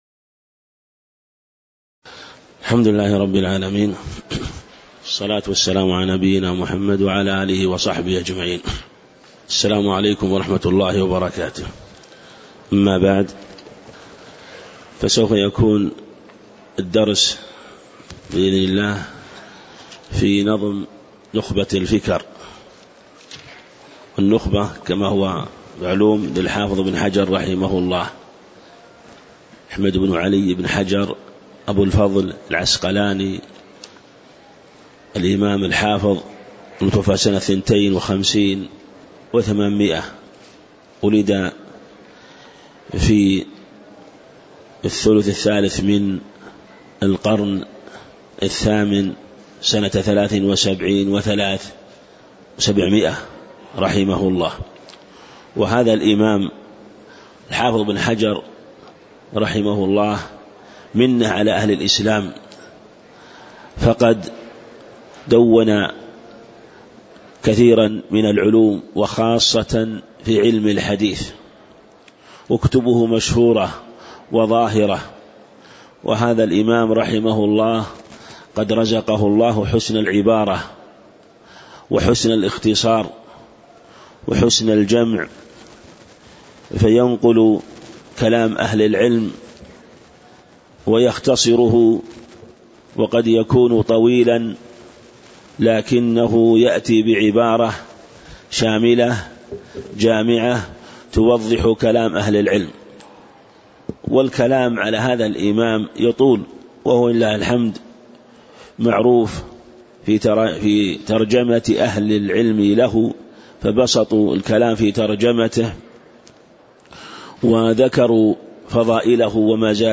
تاريخ النشر ١٣ صفر ١٤٣٨ هـ المكان: المسجد النبوي الشيخ